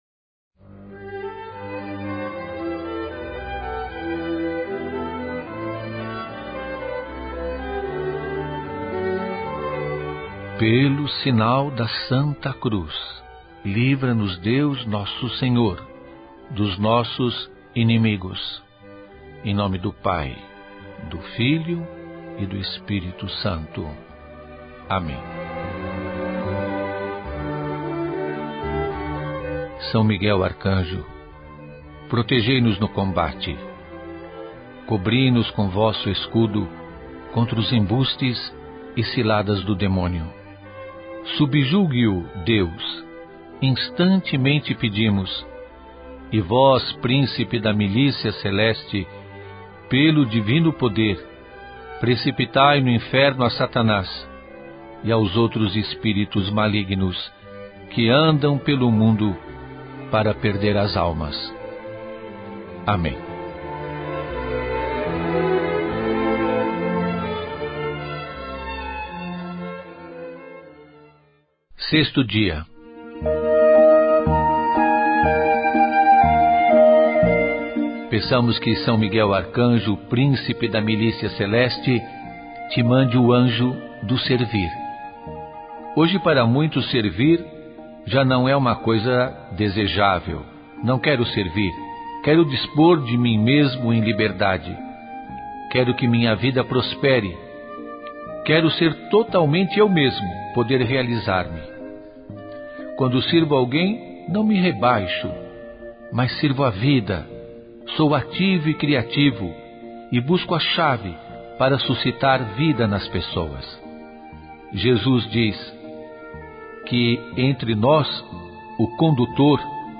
Julio Lancellotti. 6º dia: Esta novena foi produzida nos estúdios da Universidade São Judas Tadeu